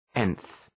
Προφορά
{enɵ}